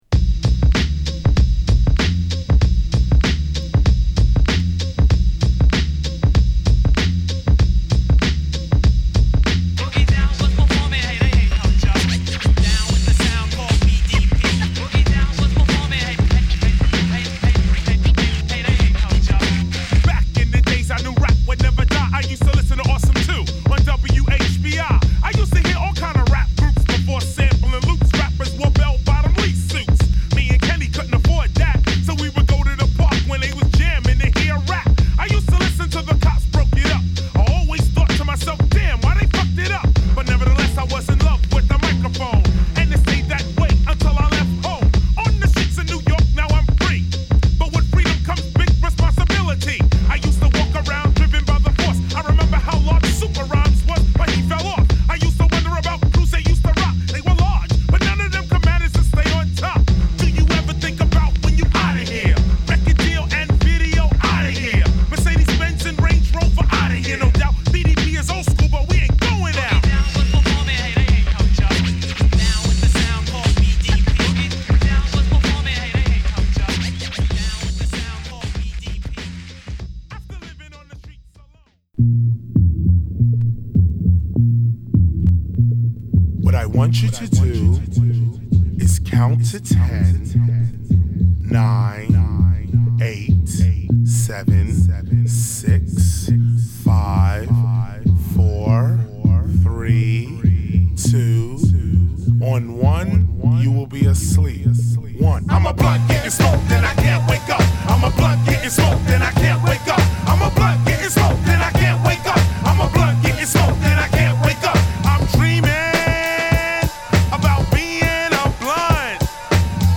＊試聴はA2→B1→7"Bです。